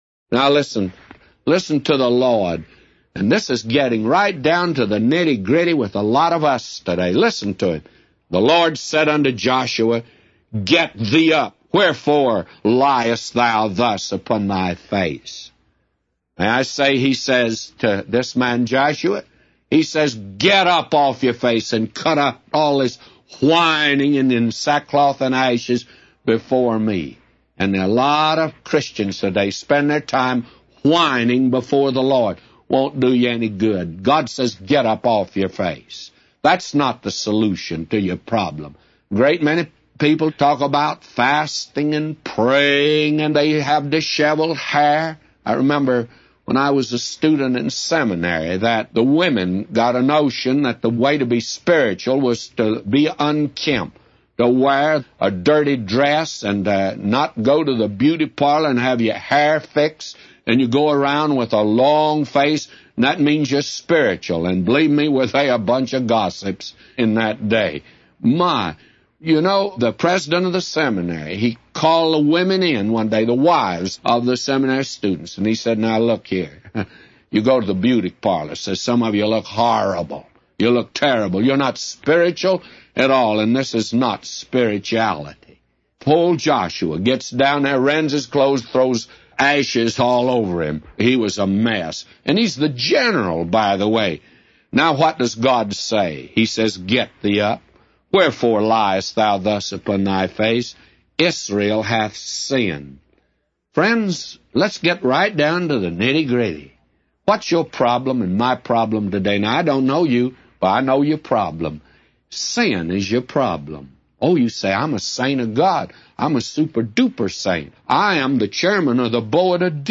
A Commentary By J Vernon MCgee For Joshua 7:4-999